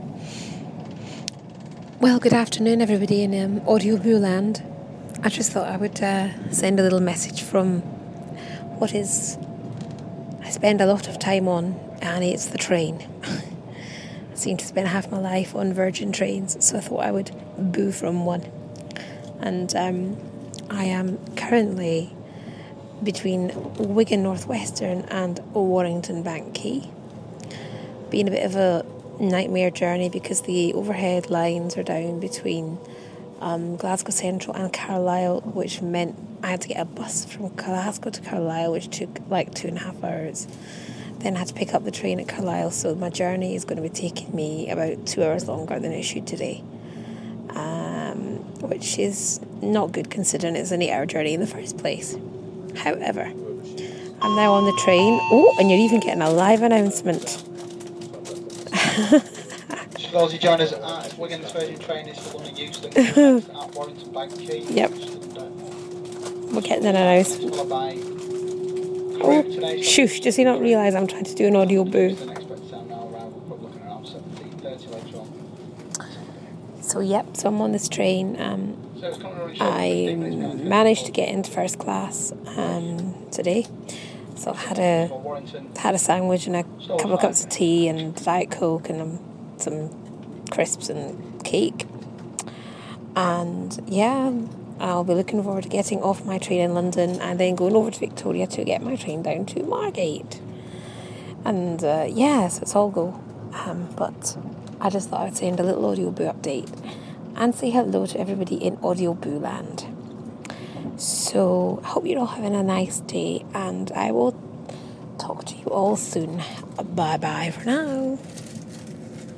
Hello from a train.